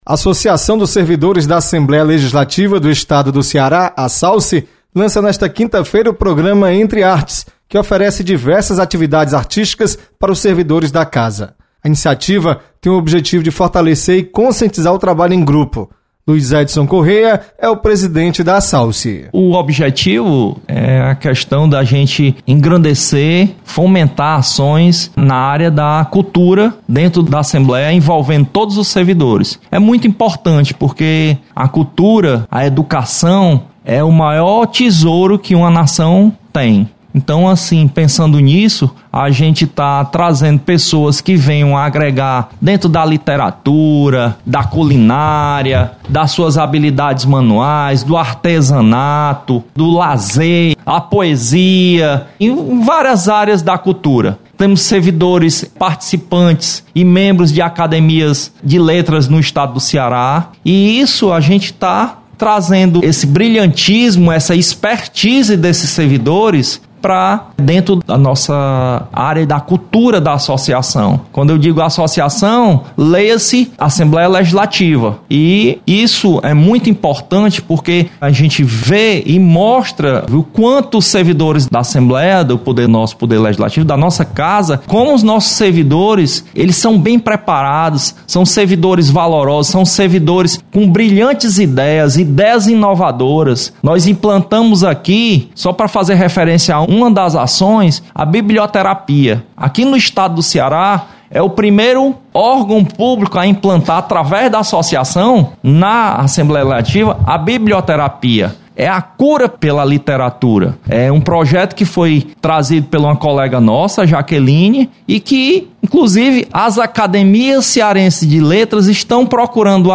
Você está aqui: Início Comunicação Rádio FM Assembleia Notícias Assalce